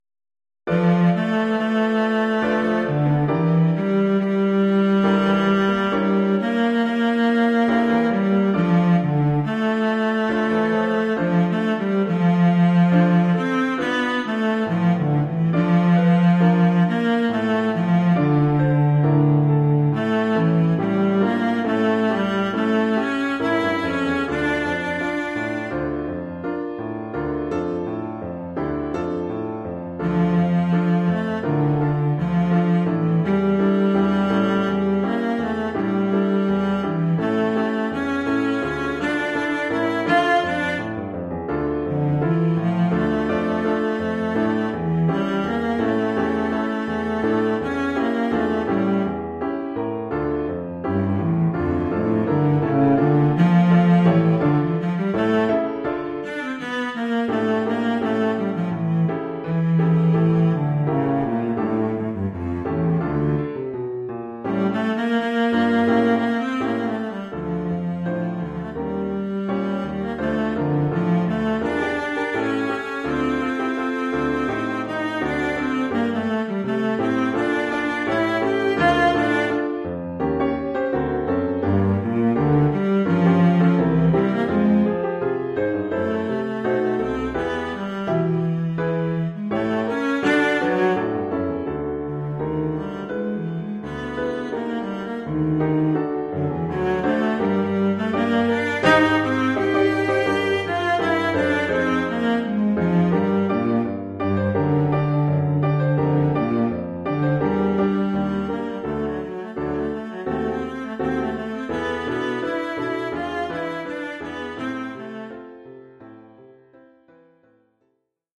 Oeuvre pour violoncelle et piano.